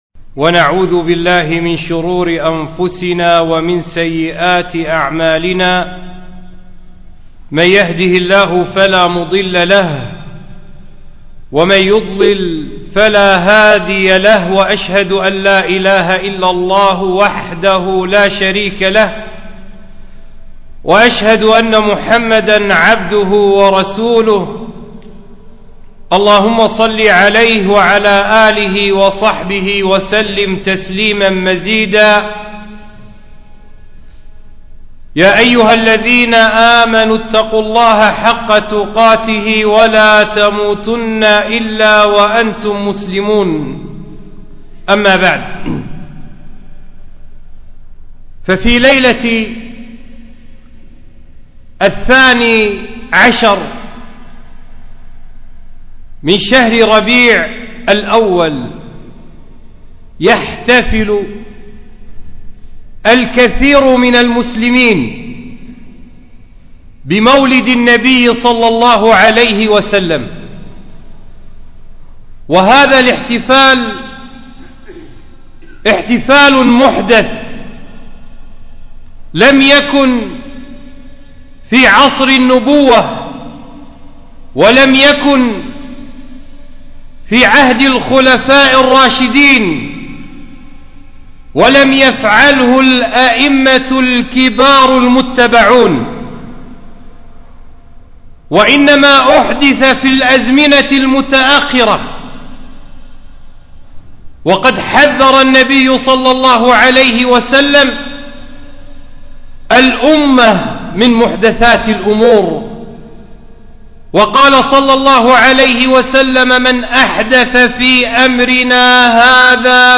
خطبة - حكم الاحتفال بالمولد